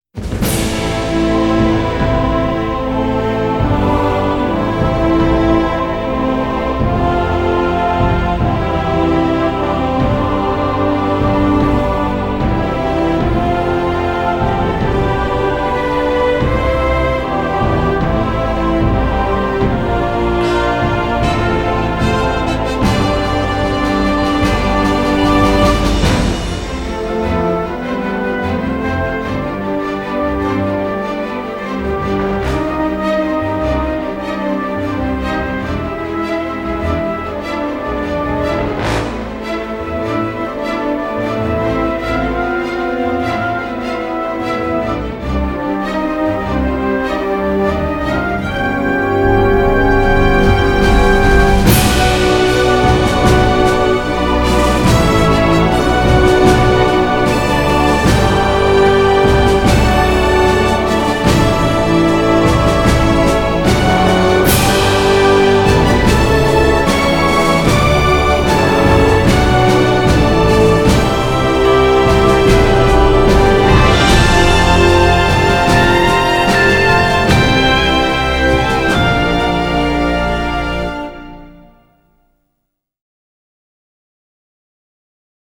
所以影片的配乐，作为大型的交响乐，其中弦乐配器和弦乐所表现的情绪都是非常出色的。